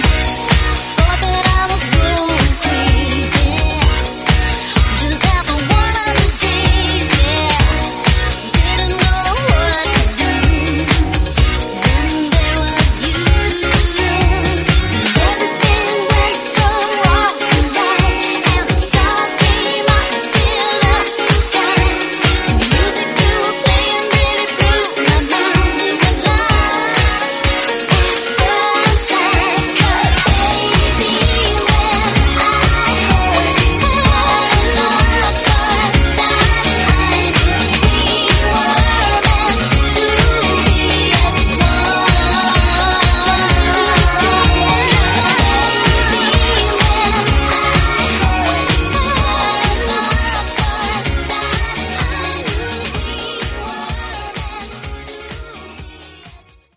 Dj Intro Outro – Get Yours Now & Add To Cart
BPM: 127 Time